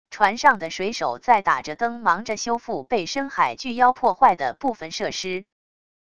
船上的水手在打着灯忙着修复被深海巨妖破坏的部分设施wav音频